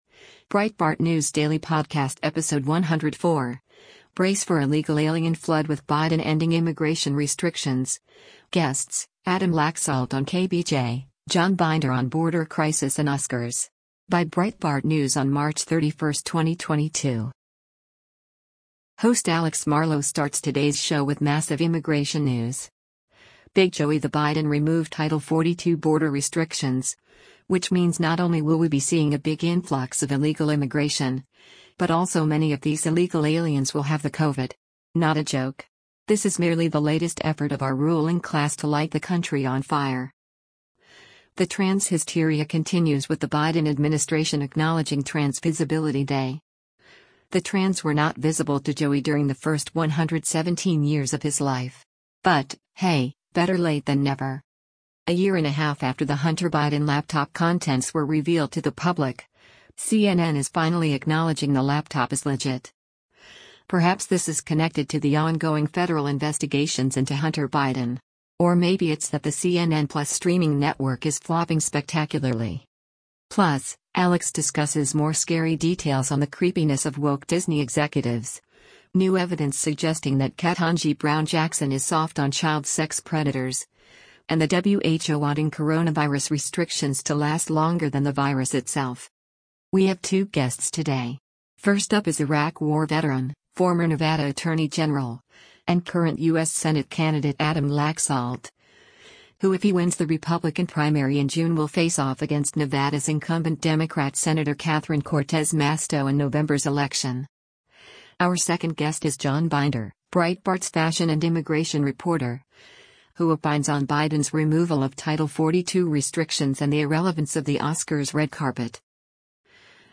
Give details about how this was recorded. The Breitbart News Daily Podcast runs Monday through Friday as a “Director’s Cut” of the SXM Patriot radio show.